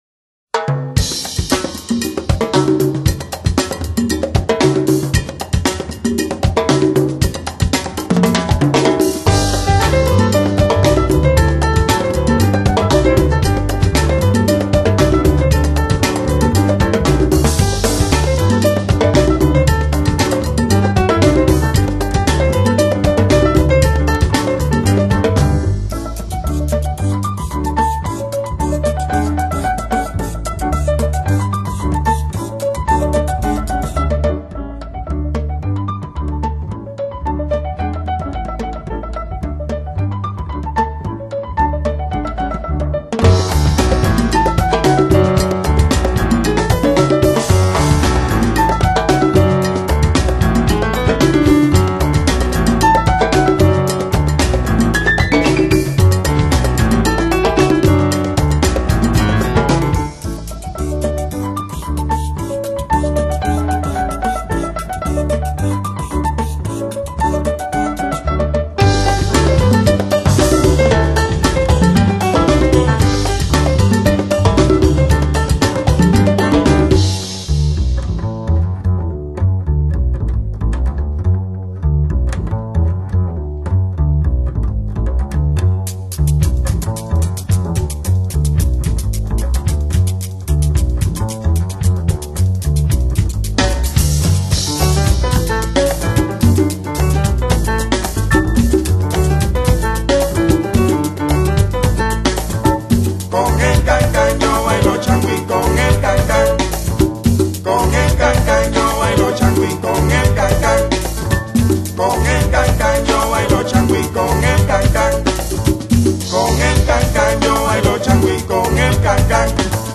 Genre : Jazz, Latin Jazz, World, Cuban, Ethno Jazz